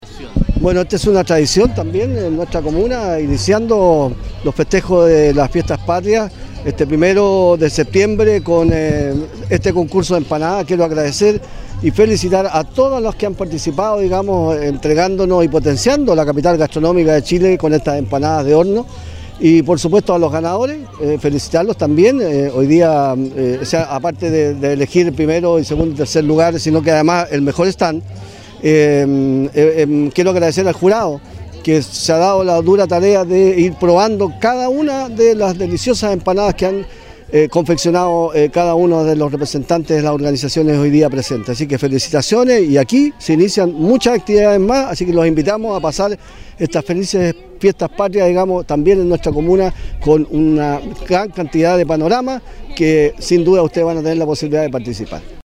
Para comenzar el mes de la patria como corresponde, en la Plaza Patricio Lynch de Concón se realizó el “XX Concurso de Empanadas 2018”, donde12 organizaciones comunitarias compitieron para ser la mejor preparación de esta tradicional comida chilena.
ALCALDE-DE-CONCON-X-EMPANADAS-1-.mp3